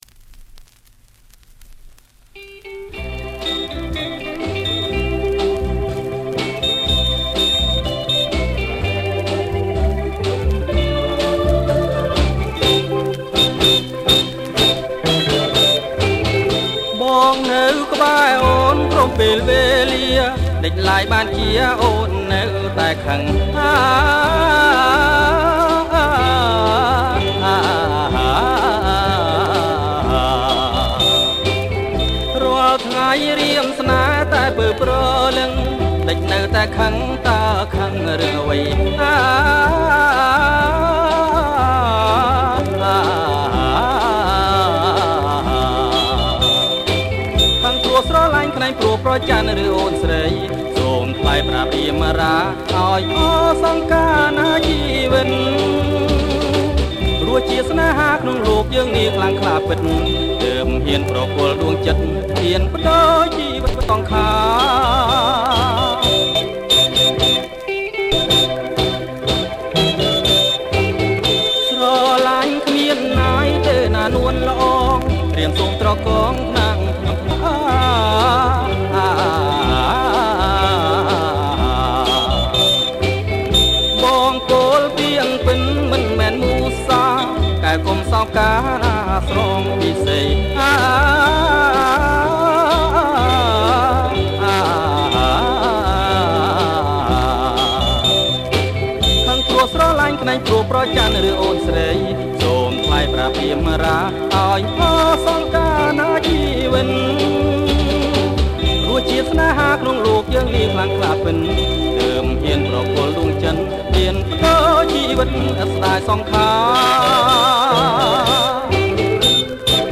ប្រគំជាចង្វាក់ Bolero Surf